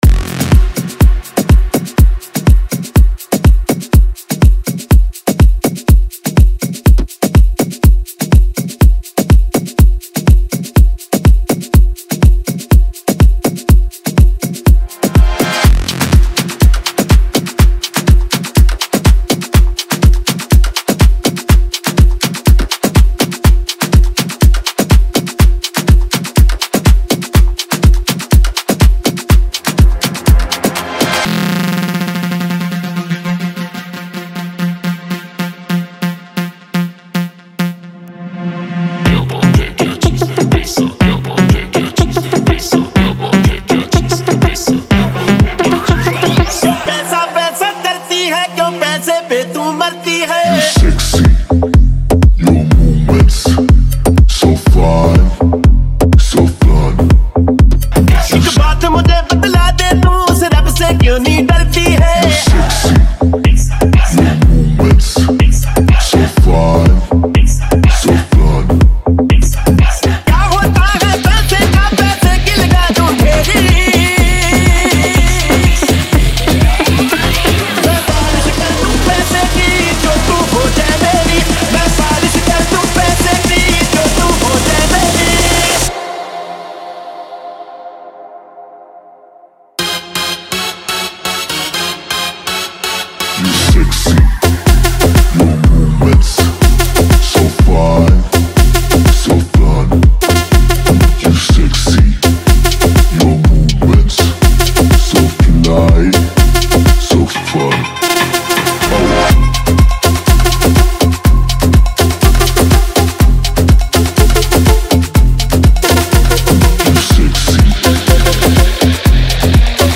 BOLLY AFRO